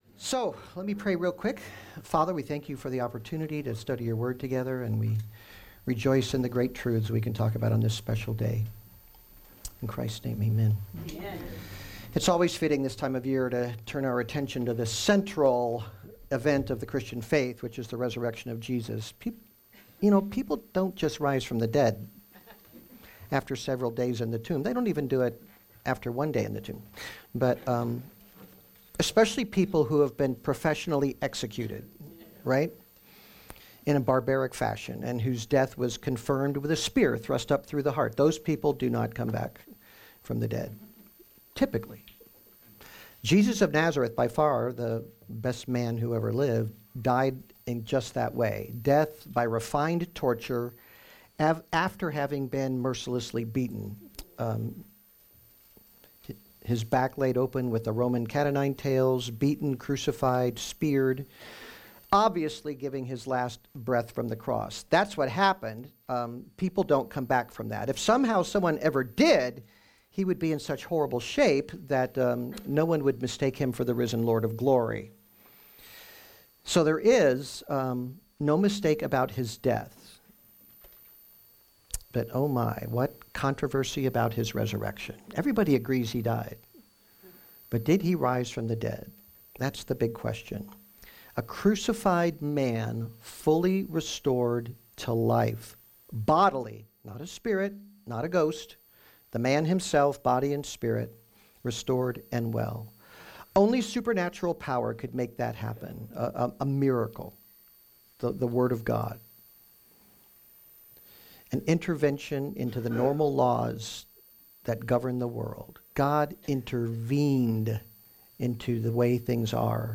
Resurrection Sunday sermon